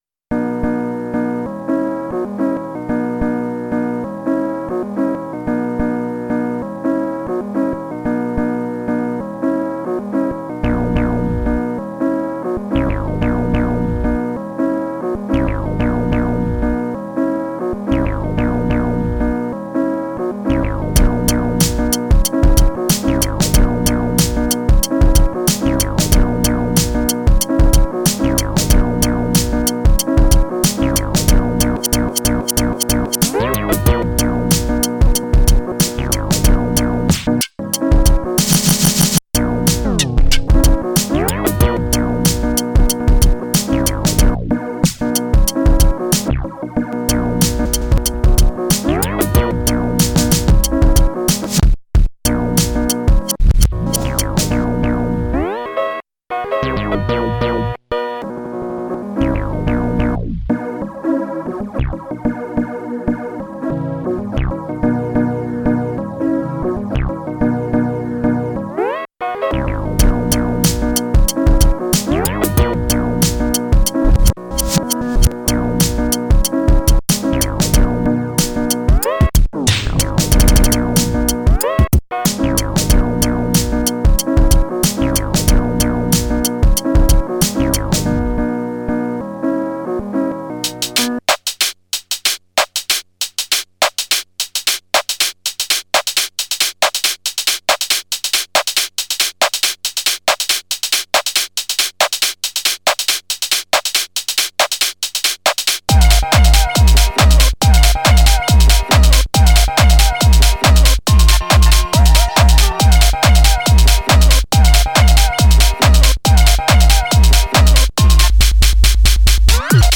Today I decided to get away from my desk and grabbed my PO-133, phone, necessary cables, cassette player, and some old Walkman headphones.
I sampled from a cassette (labeled “Easy Listening,” likely someone’s old mixtape) that I bought yesterday at a thrift store for $0.25. Everything else I sampled from Garageband on my phone, plus a beatboxed hihat that i recorded in with the PO-133’s onboard mic.
As for the track itself, it’s a teeny-tiny DJ set, with three micro ideas one after the other, all played live in one take.